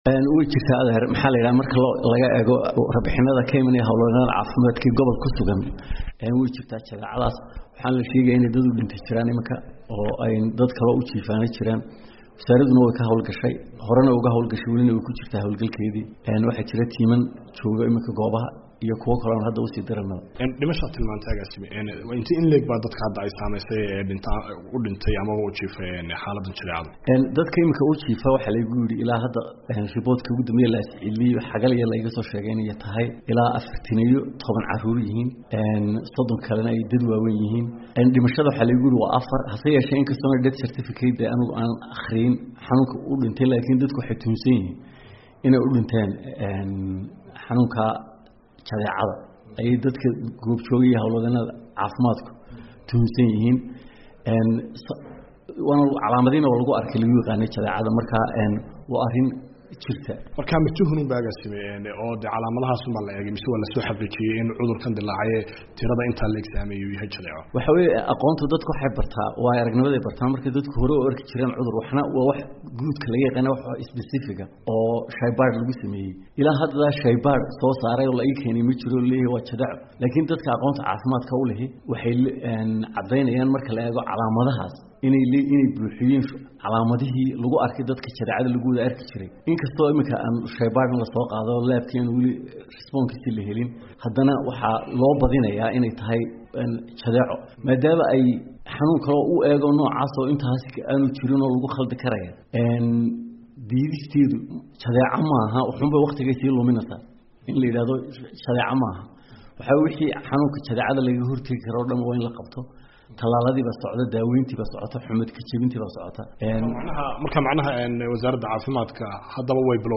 Agaasimaha guud ee wasaarada caafimaadka ee Somaliland Saleebaan Jaamac Diiriye oo u waramay VOA ayaa sheegay inay ku hawlan yihiin sidii ay xaalada ula tacaali lahaayeen.